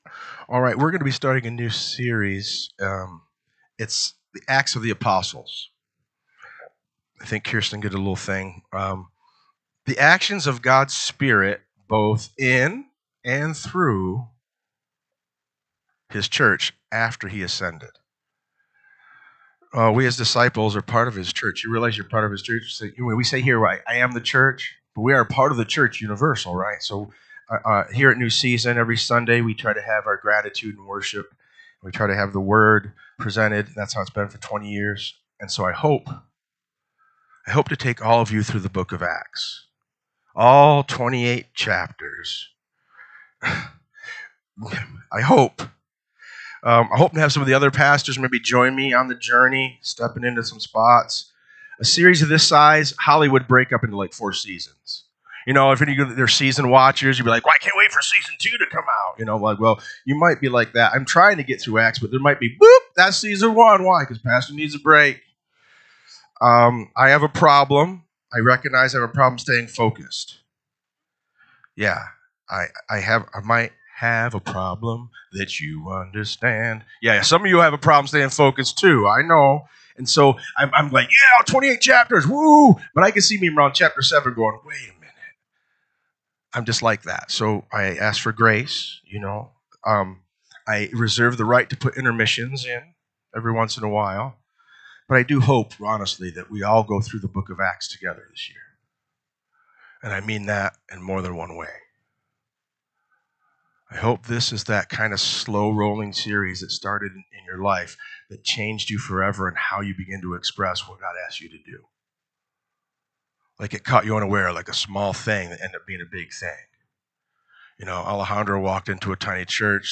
NSCF Sermons Online Acts of The Apostles Ep. 1 Jul 13 2025 | 00:53:40 1x 00:00 / 00:53:40 Subscribe Share RSS Feed Share Link Embed